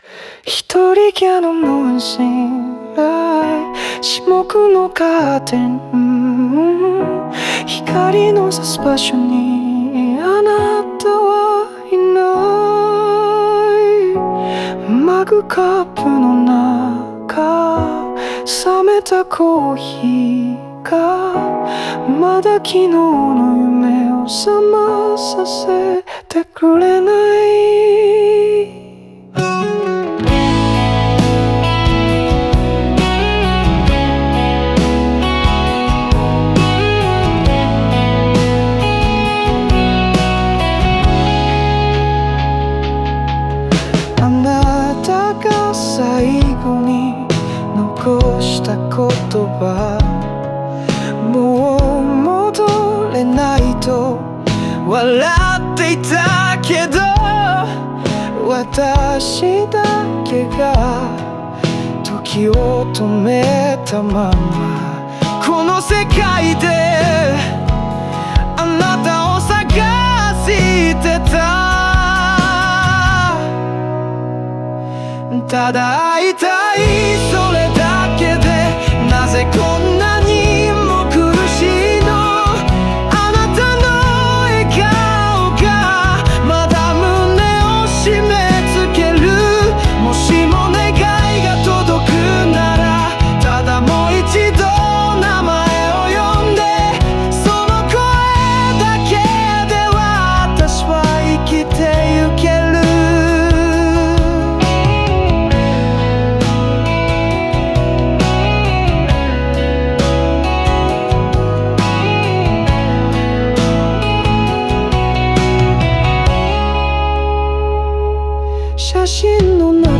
オリジナル曲♪
日常の静けさや、残された記憶の中で揺れる心情が、静かなピアノとギターにのせて丁寧に表現されている。
音楽は感情の波に寄り添い、静かな絶望から儚い希望までを描き切っている。